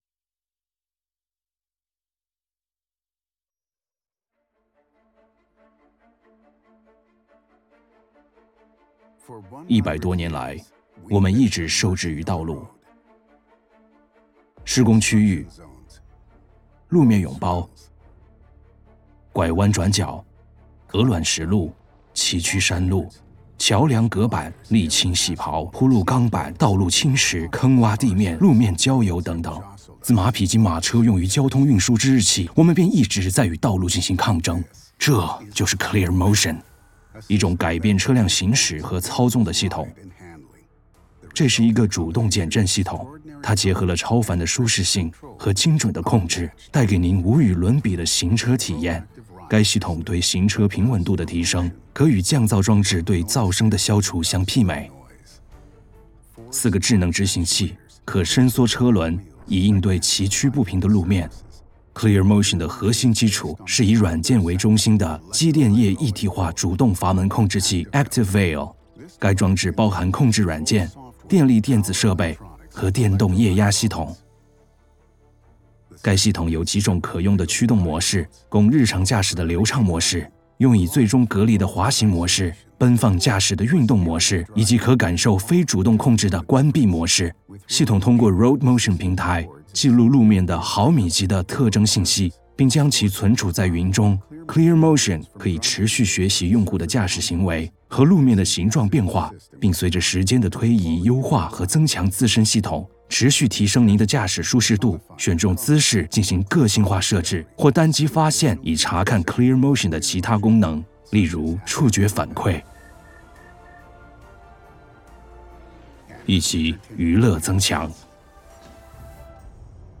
Commercial, Young, Natural, Friendly, Warm
Explainer